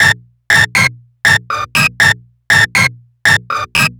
TSNRG2 Lead 014.wav